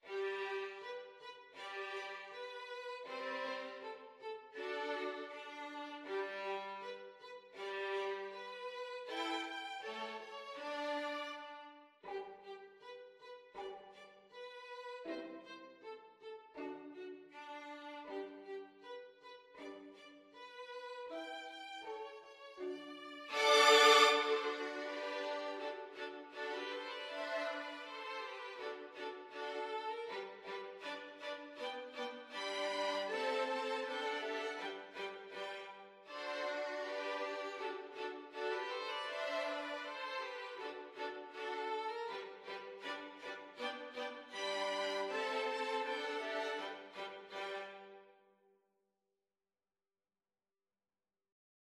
2/4 (View more 2/4 Music)
Andante
Classical (View more Classical Violin Quartet Music)